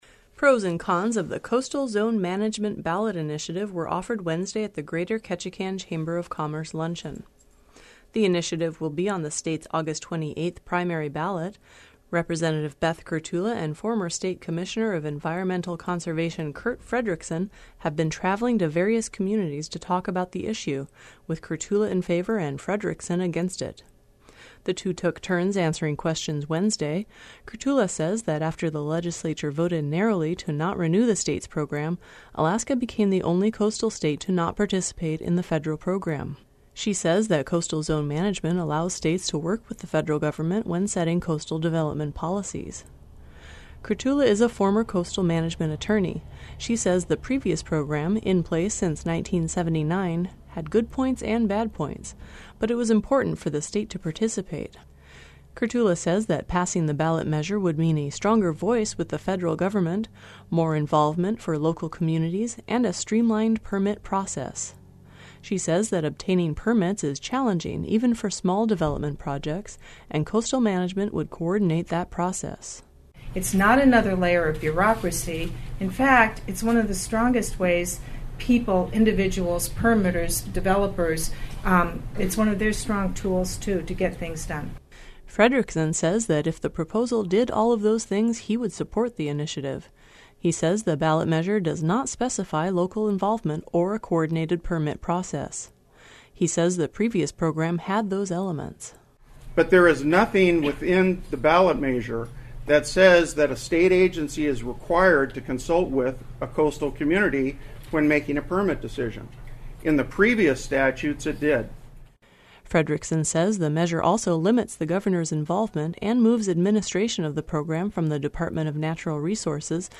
Pros and cons of the Coastal Zone Management ballot initiative were offered Wednesday at the Greater Ketchikan Chamber of Commerce luncheon
The two took turns answering questions Wednesday.